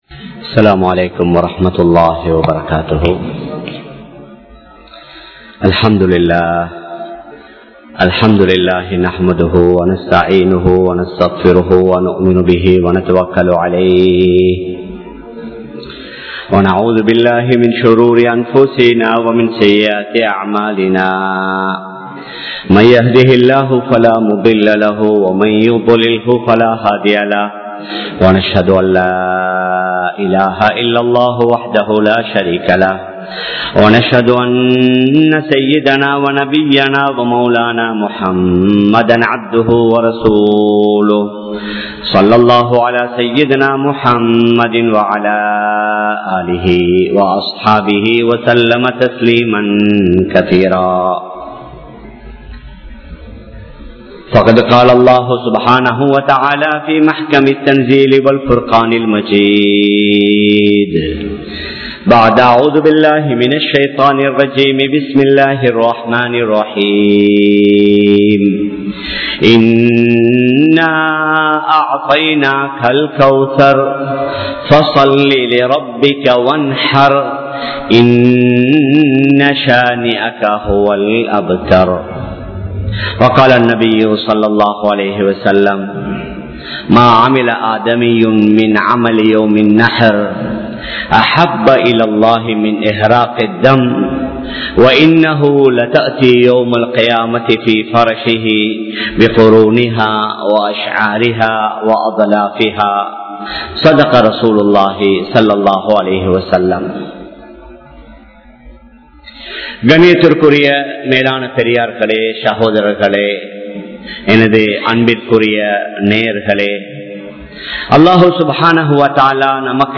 Ulhiyyavin Sattangal (உழ்ஹிய்யாவின் சட்டங்கள்) | Audio Bayans | All Ceylon Muslim Youth Community | Addalaichenai
Colombo 02, Wekanda Jumuah Masjidh